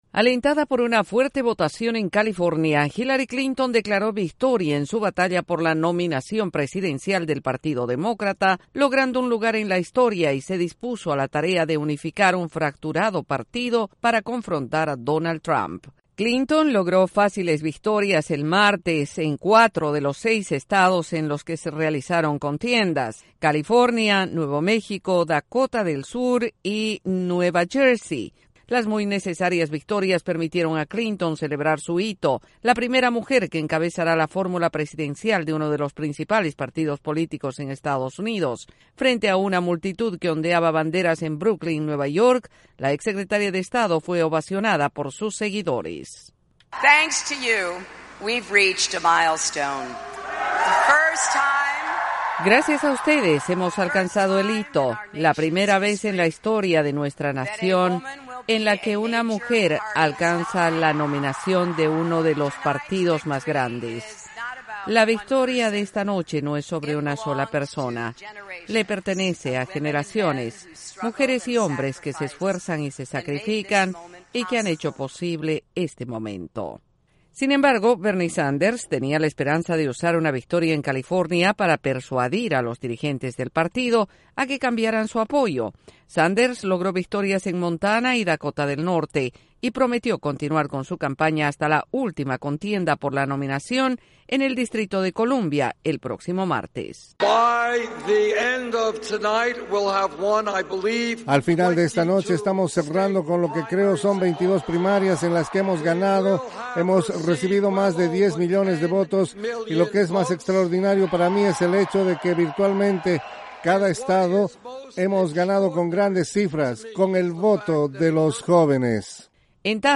Hillary Clinton y Donald Trump sellan sus respectivos caminos hacia la nominación presidencial de Estados Unidos. Desde la Voz de América en Washington DC informa